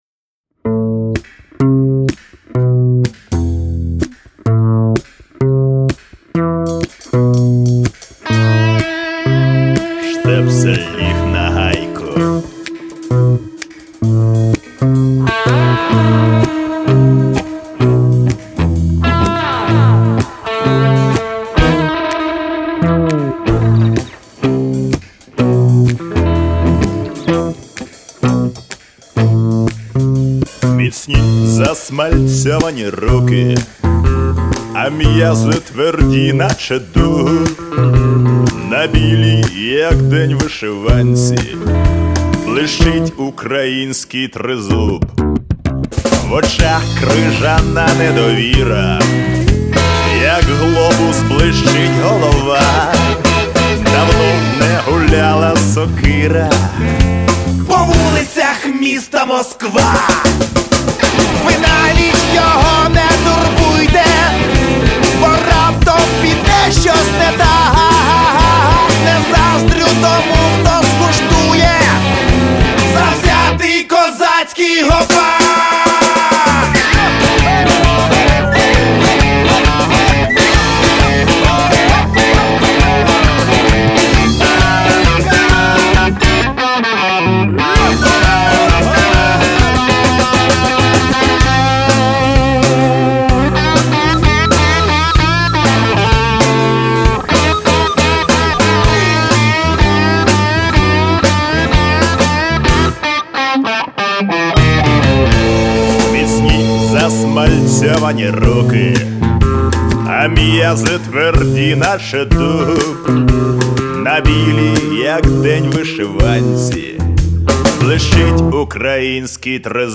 Категорія: Rock